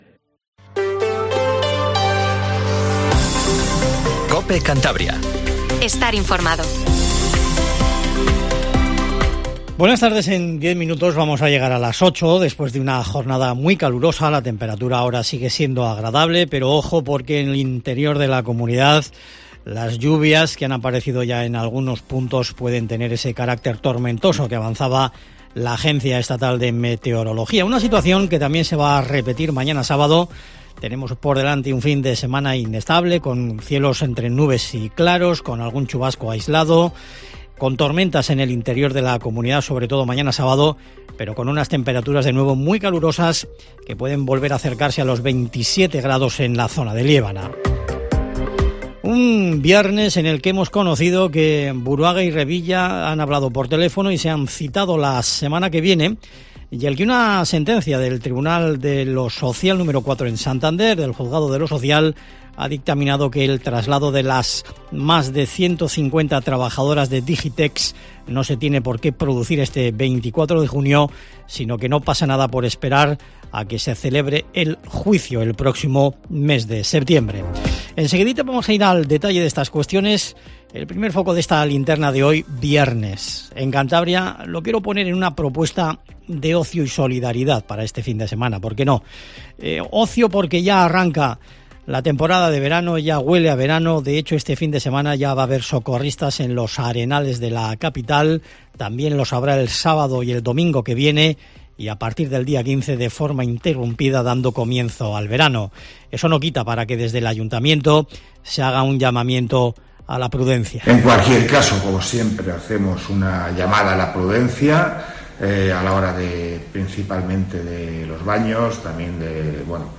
Informativo Tarde COPE CANTABRIA